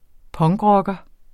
Udtale [ ˈpʌŋgˌʁʌgʌ ]